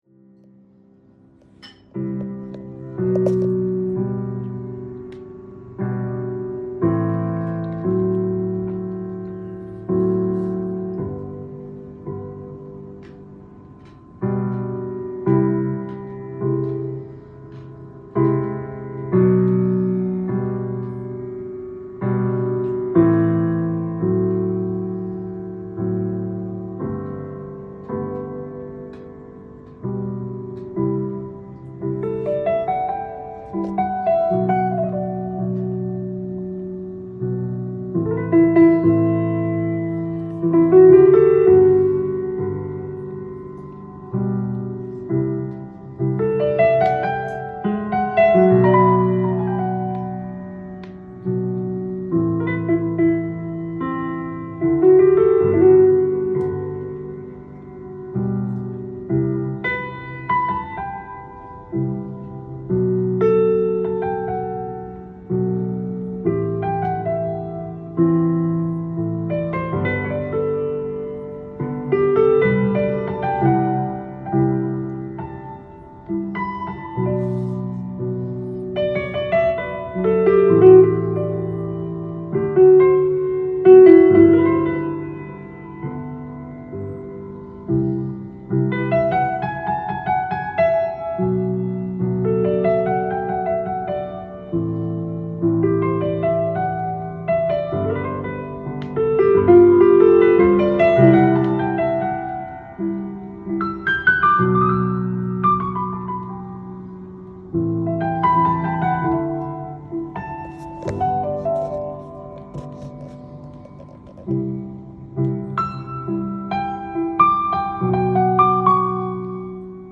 ジャンル：JAZZ-PIANO
店頭で録音した音源の為、多少の外部音や音質の悪さはございますが、サンプルとしてご視聴ください。